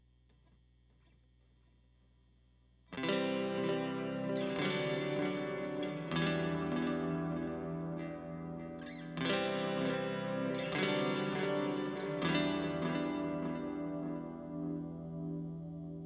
Guitar_80s_78bpm_Em
Guitar_80s_78bpm_Em.wav